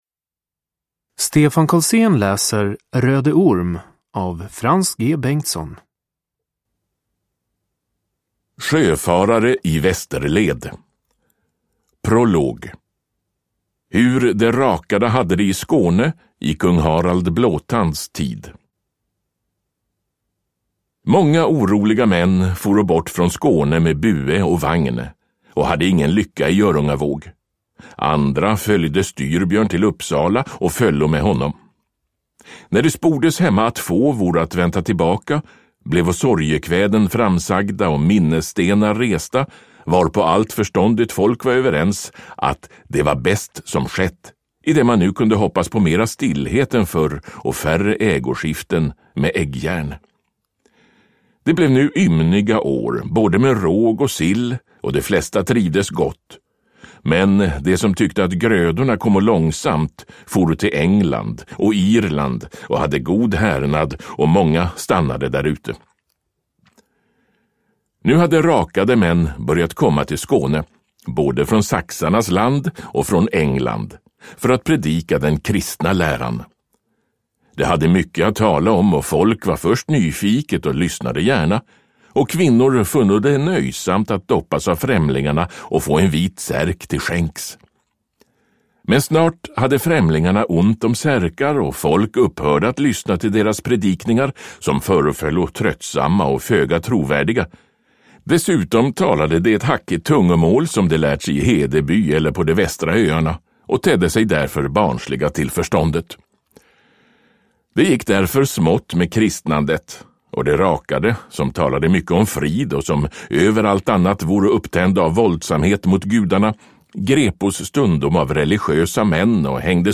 Röde Orm : en berättelse från okristen tid – Ljudbok – Laddas ner